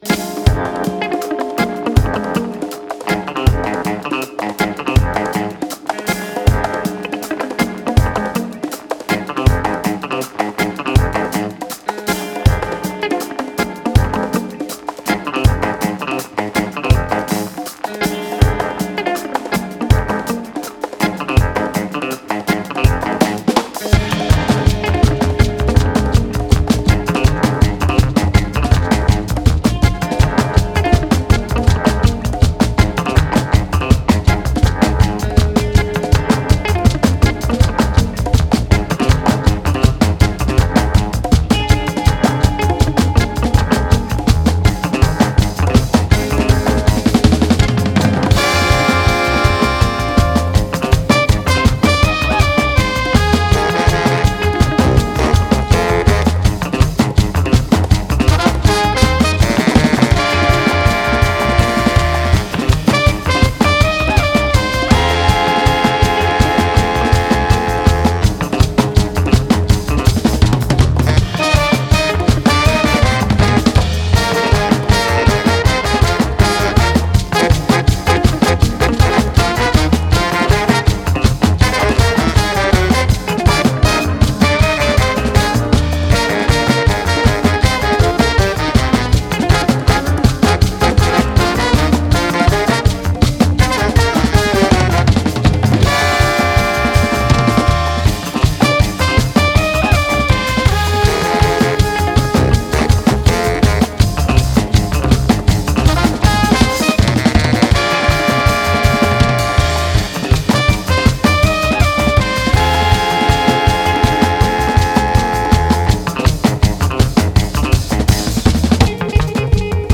рок,рэгги ,фанк